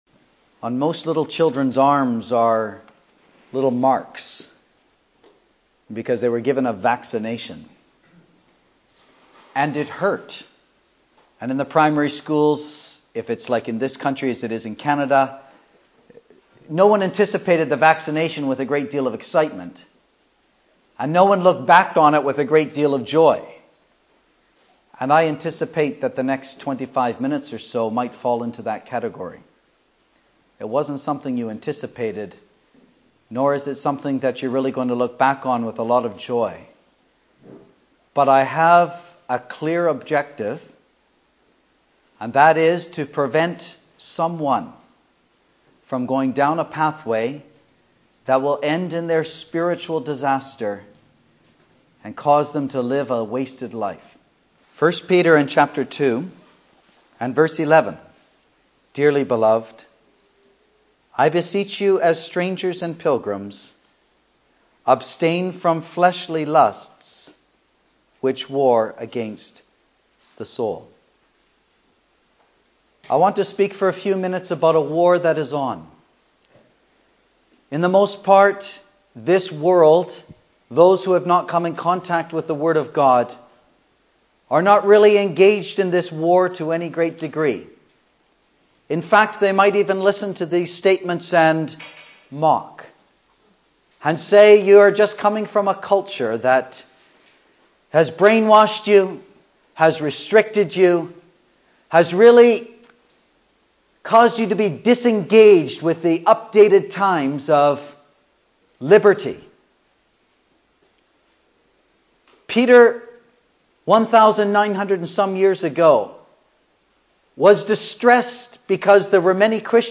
A stirring and vital message to preserve us in a day of evil (Message preached in Bicester, Easter 8th April 2012)